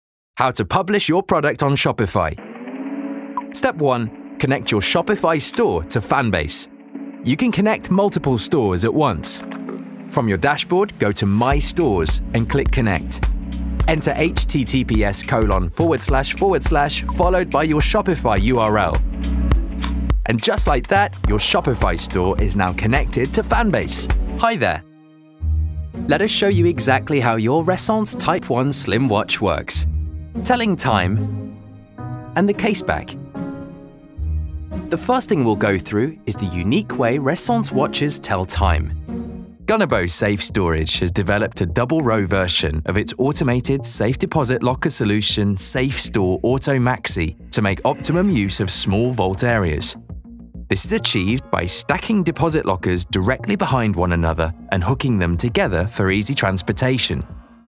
Inglés (Británico)
Comercial, Natural, Cool, Accesible, Amable
Explicador